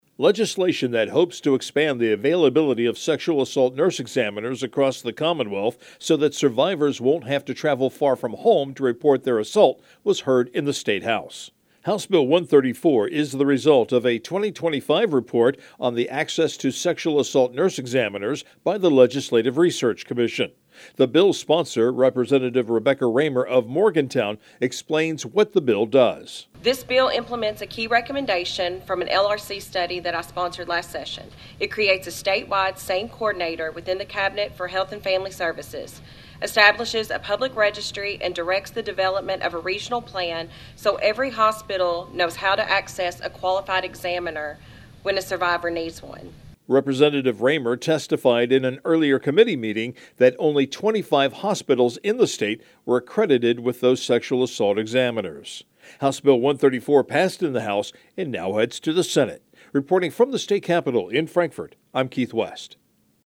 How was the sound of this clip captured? LRC Public Information offers audio feeds over the Internet to help radio news directors obtain timely actualities and pre-produced news spots.